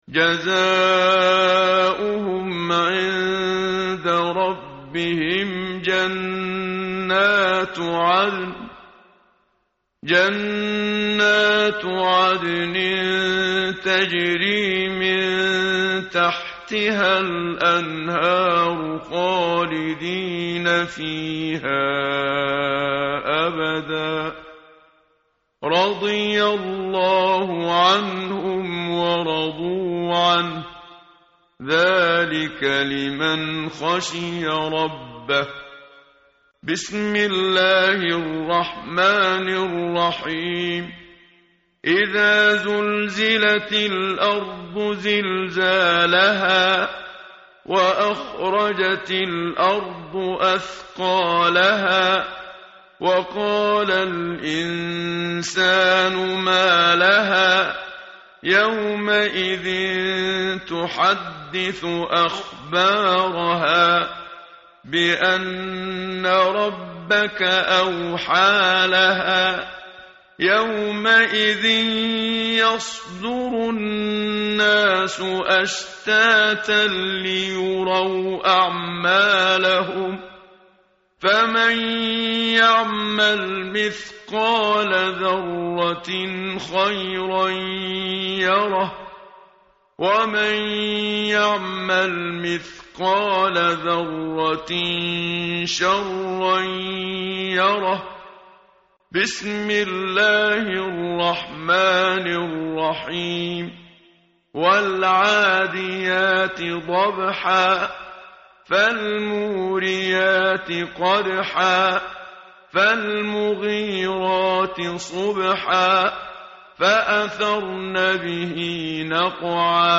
متن قرآن همراه باتلاوت قرآن و ترجمه
tartil_menshavi_page_599.mp3